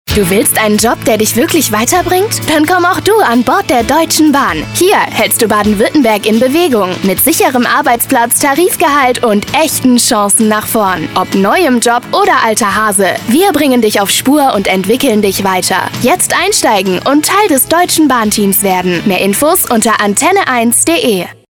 In die Arbeit vorm Mikrofon ist sie quasi hineingewachsen und wird auch heute noch mitunter als aufgeweckte junge "Kinderstimme" in der Werbung oder auch für Hörspiele oder Synchron gebucht.
Funk 2026